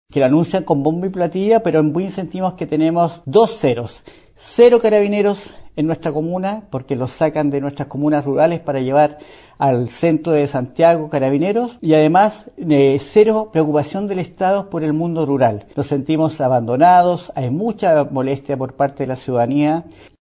Frente a lo ocurrido, el alcalde de la comuna, Miguel Araya, cuestionó la actualización del plan Calle Sin Violencia anunciada por las autoridades la semana recién pasada. El jefe comunal indicó sentirse abandonado y que el Estado no está dando el ancho frente al tema de seguridad.